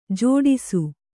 ♪ jōḍisu